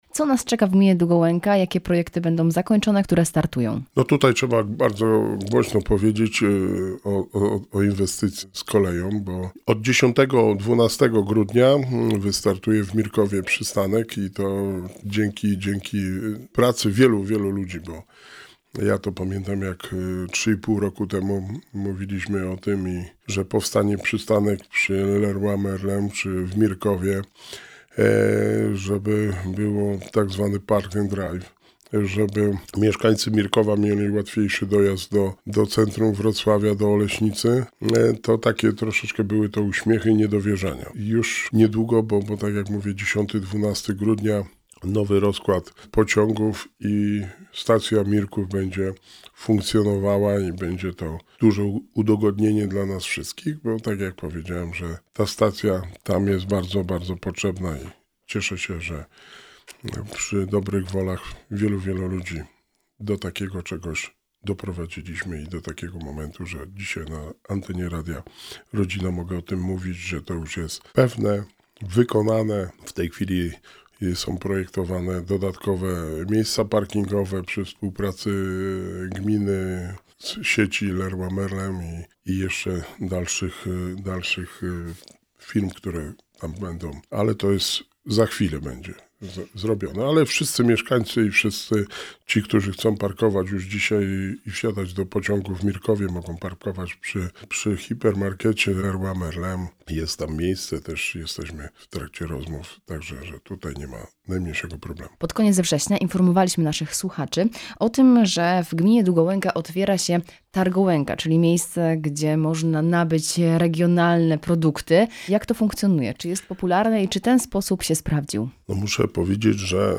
Rozmowa z Wójtem Gminy Długołęka
W studiu Radia Rodzina gościł Wojciech Błoński, wójt Gminy Długołęka. Rozmawiamy o otwarciu Gminnego Ośrodka Kultury, Spotkaniu Opłatkowym połączonym z Jarmarkiem Bożonarodzeniowym, a także o aktualnych i przyszłych inwestycjach.